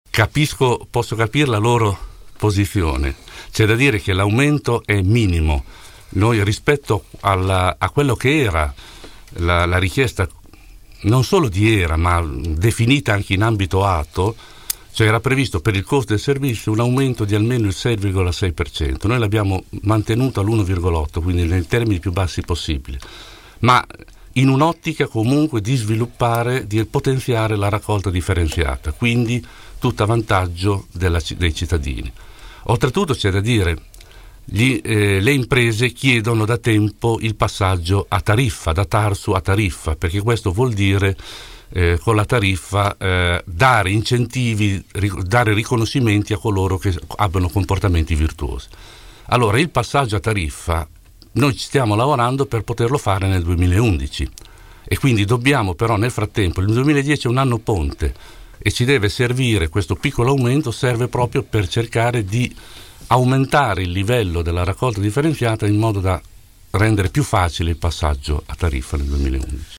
L’assessore al bilancio ai nostri microfoni parla di tagli, tasse e investimenti.